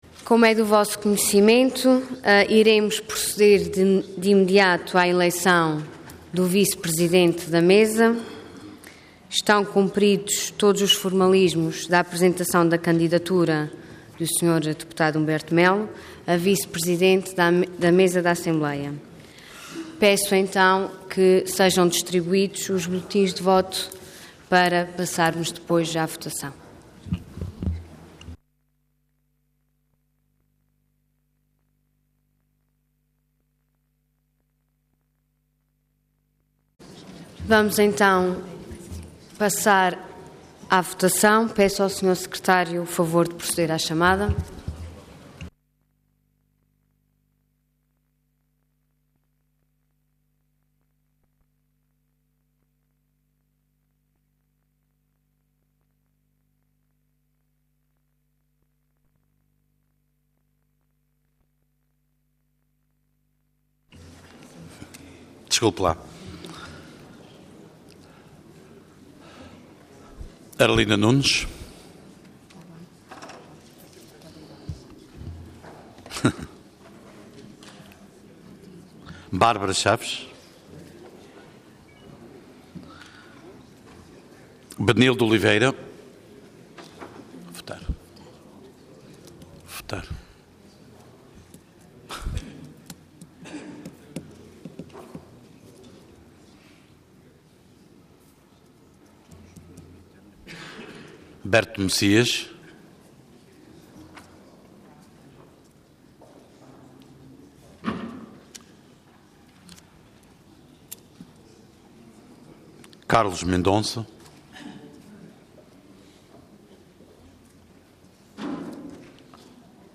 Intervenção Orador Ana Luísa Luís Cargo Presidente da Assembleia Regional Entidade Mesa da Assembleia